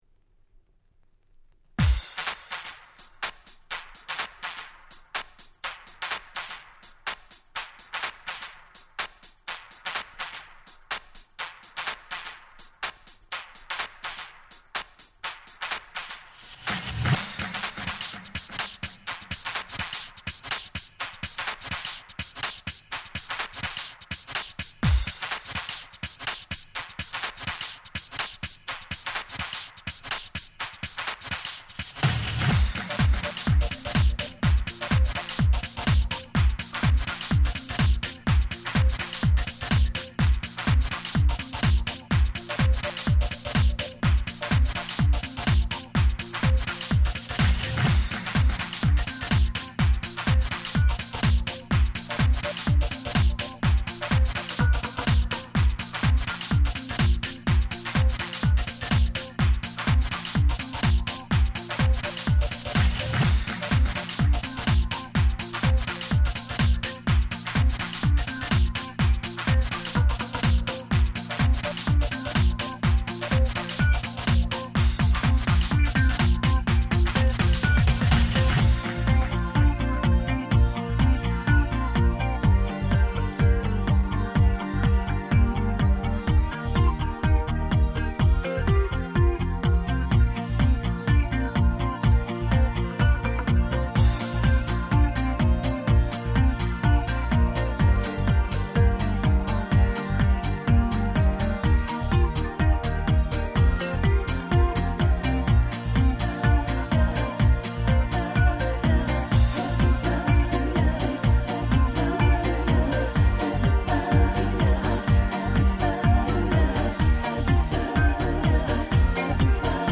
02' Super Nice Cover Vocal House !!